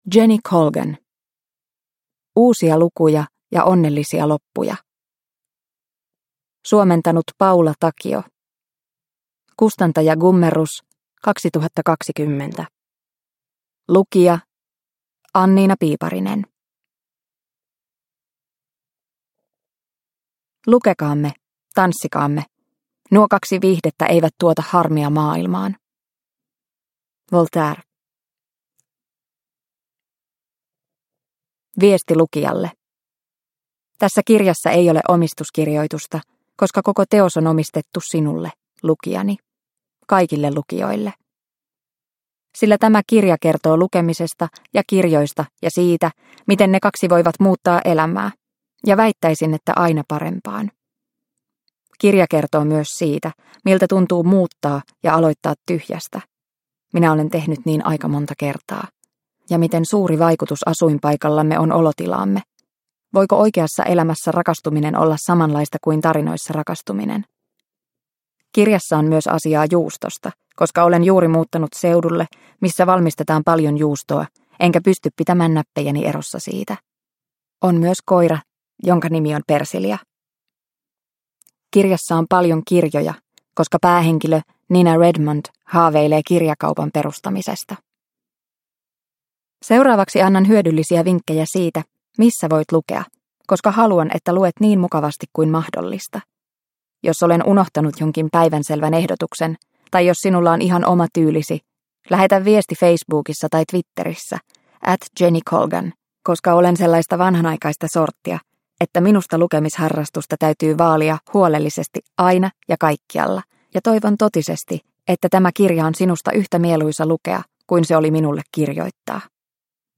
Uusia lukuja ja onnellisia loppuja – Ljudbok – Laddas ner